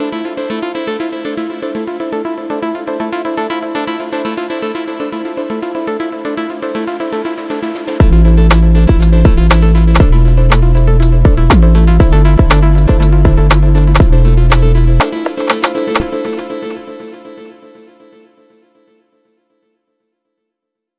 Podcast Intro
Podcast-Intro-Short.wav